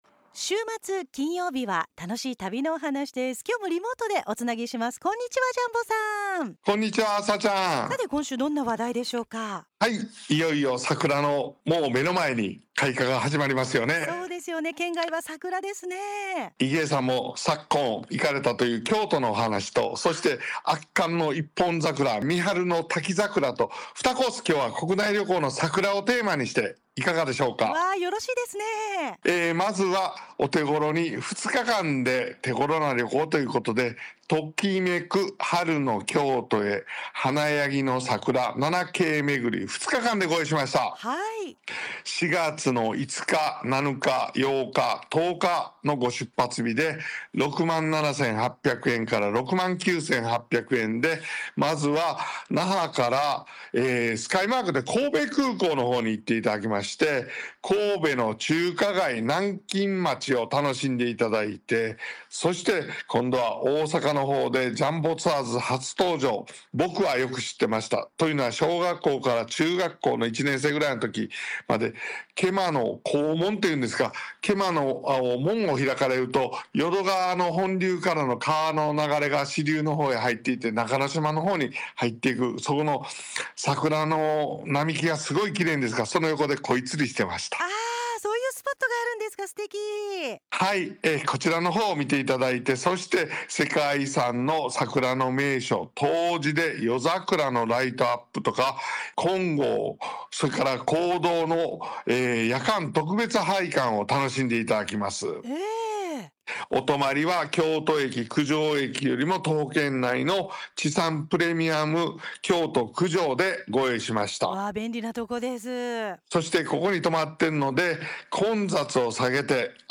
カテゴリ： ジャンボのゆかいな旅トーク（ラジオ）, 国内 Japan, 関西 Kansai